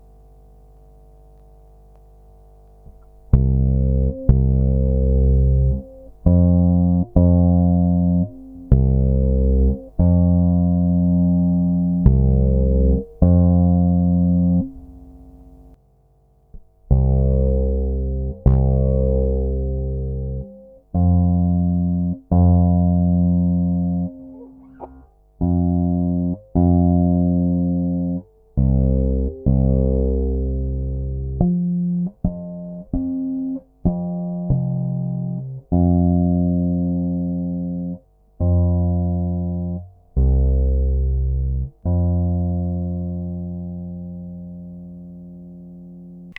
Audiovox 736 replica, the first solid body bassguitar!